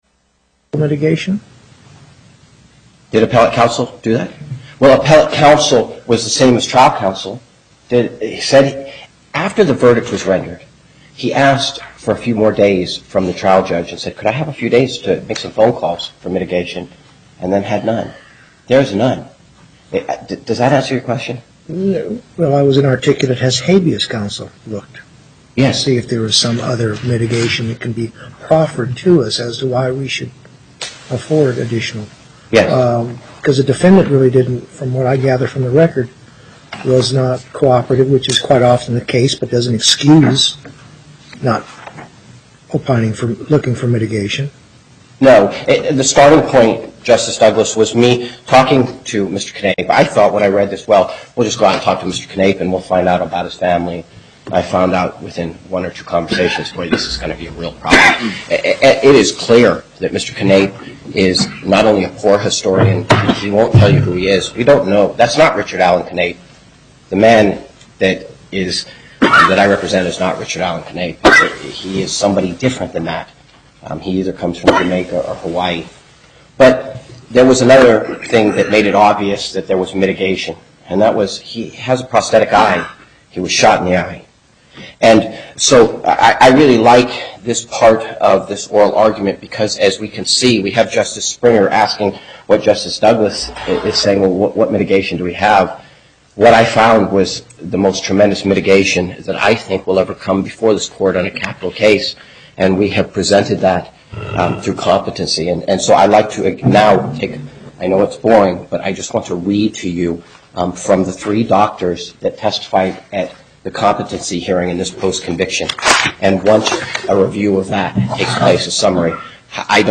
Location: Las Vegas Before the En Banc Court, Justice Hardesty presiding
as counsel for Appellant
as counsel for Respondent